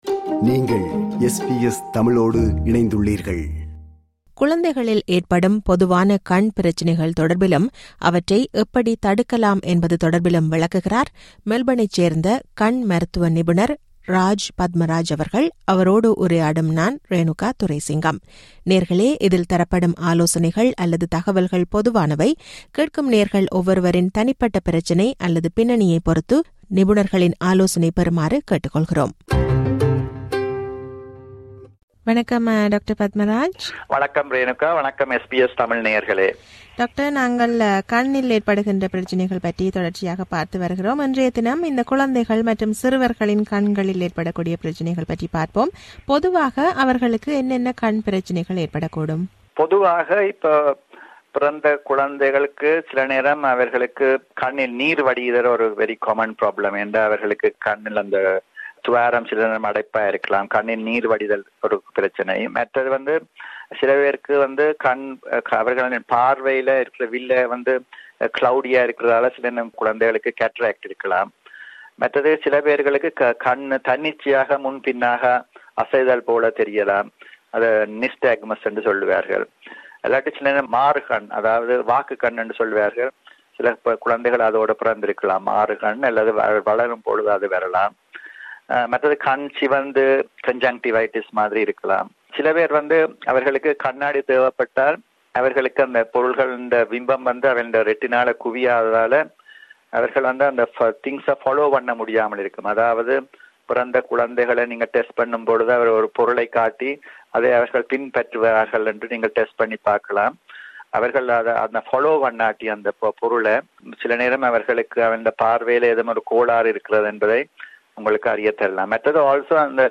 மெல்பனைச் சேர்ந்த கண் மருத்துவ நிபுணர்